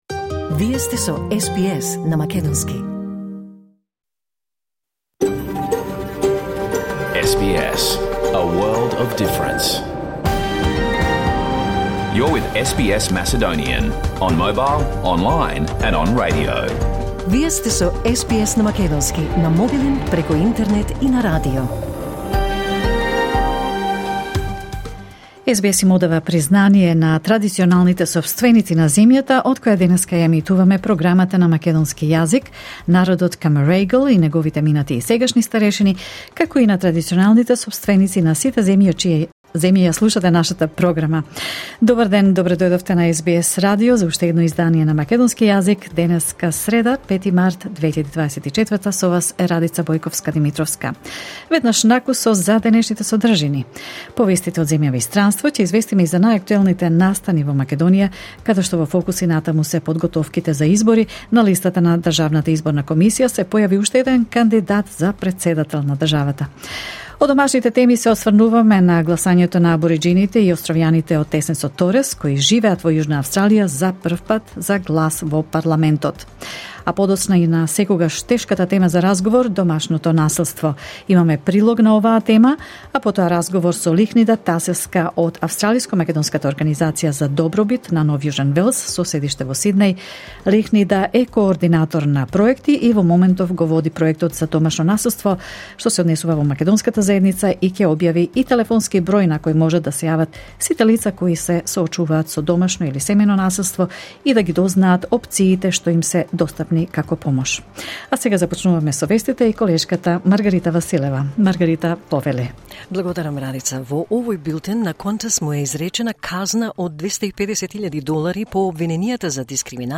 SBS Macedonian Program Live on Air 6 March 2024